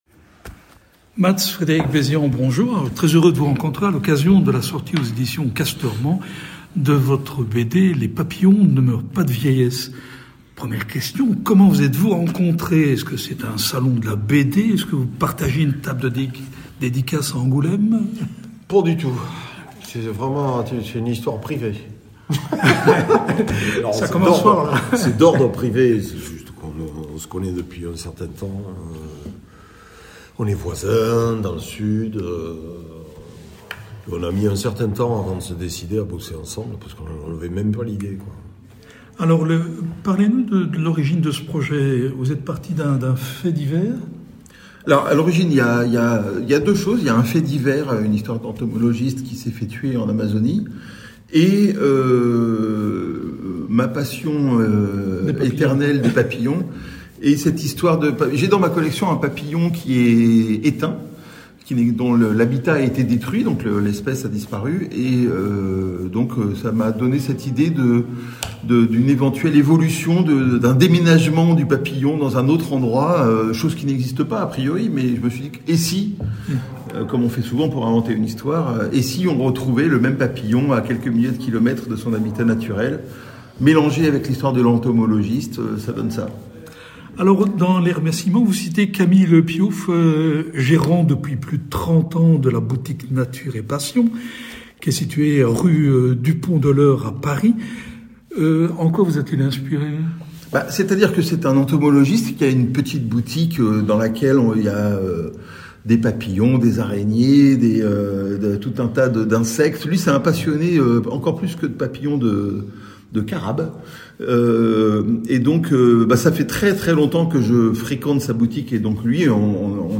Rencontre avec les auteurs